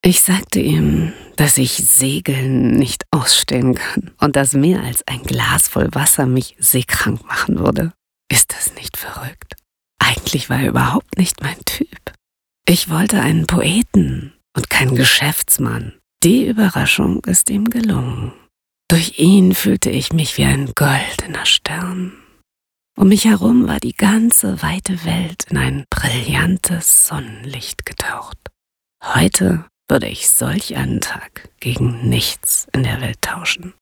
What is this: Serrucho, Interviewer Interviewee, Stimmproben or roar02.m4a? Stimmproben